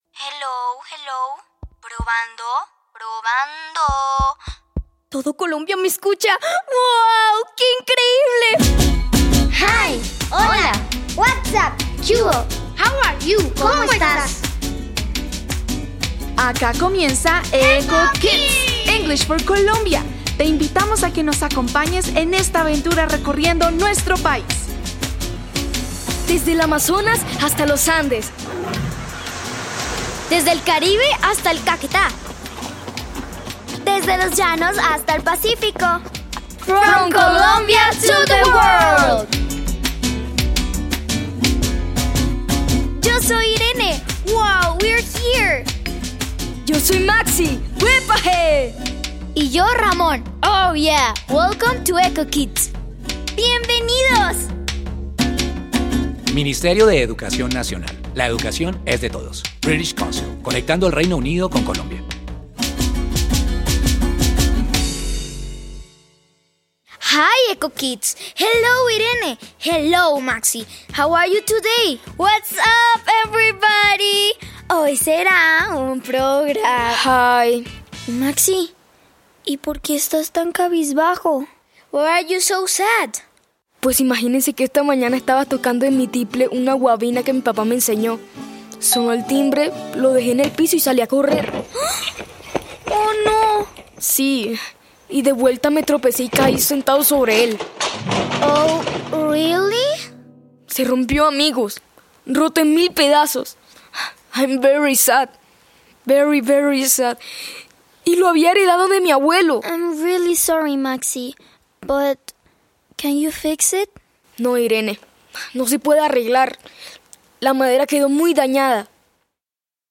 Introducción Este recurso ofrece un episodio radial de Eco Kids sobre un instrumento musical tradicional. Presenta expresiones en inglés y elementos culturales para fortalecer la comprensión auditiva.